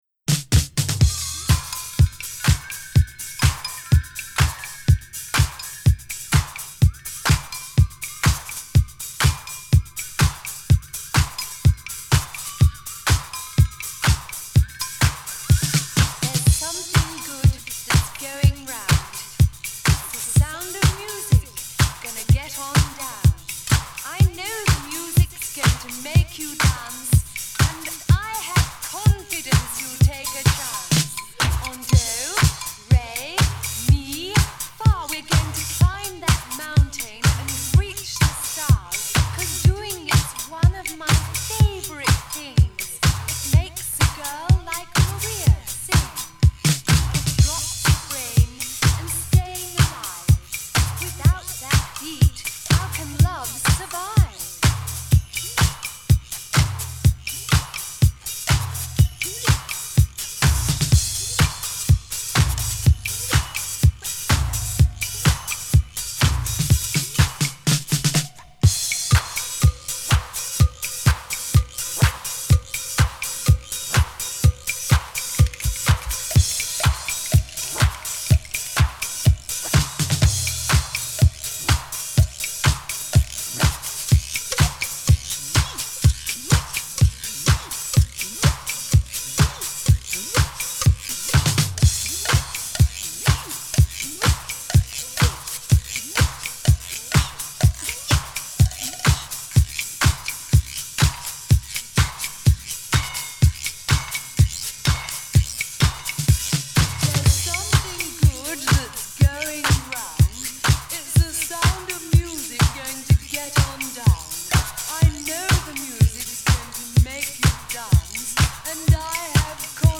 Vinyl rip